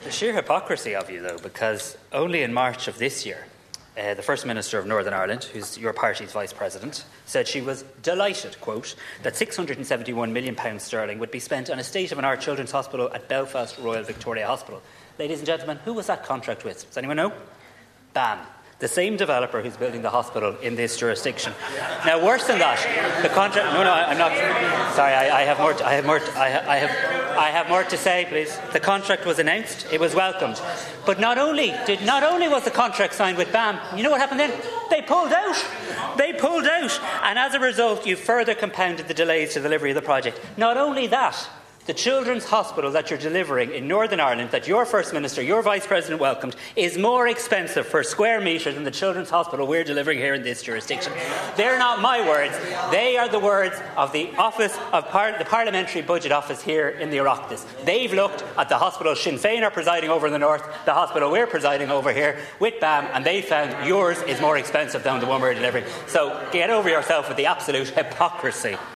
In his response, Tánaiste Harris compared a similar deal that was recently made between BAM and Sinn Fein in Northern Ireland: